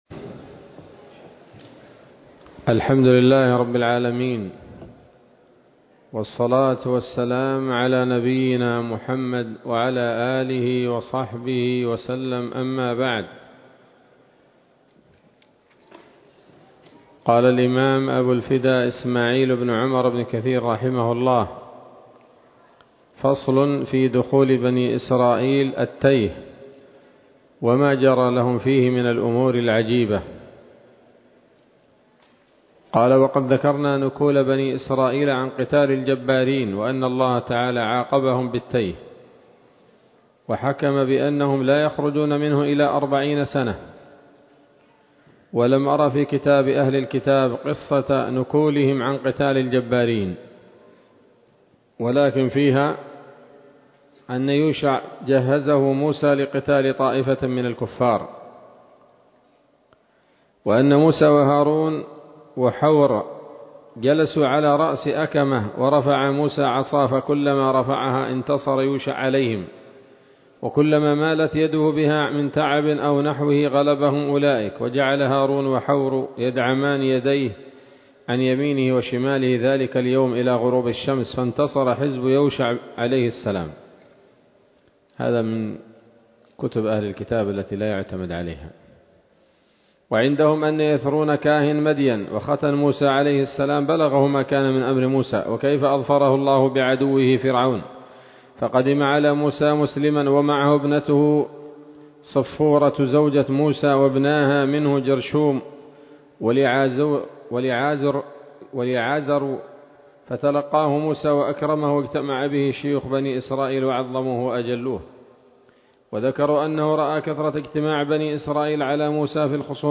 ‌‌الدرس السادس والتسعون من قصص الأنبياء لابن كثير رحمه الله تعالى